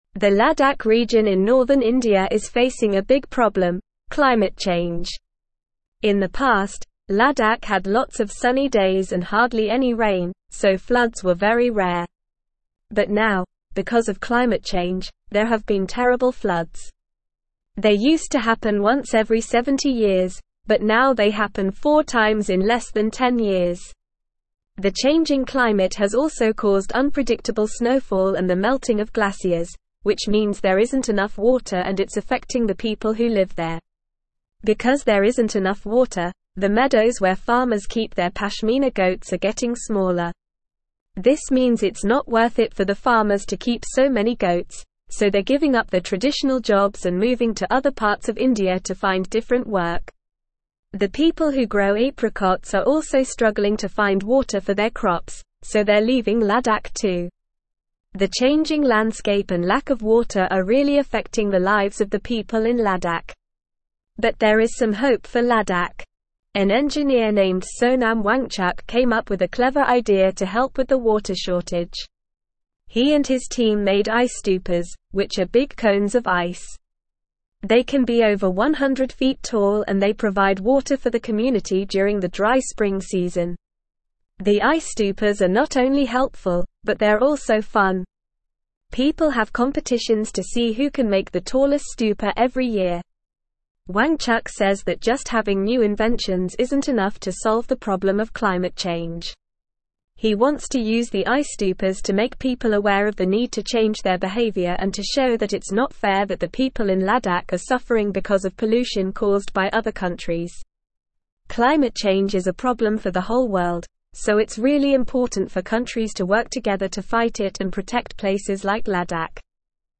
Normal
English-Newsroom-Upper-Intermediate-NORMAL-Reading-Ladakhi-People-Battle-Climate-Change-with-Ice-Stupas.mp3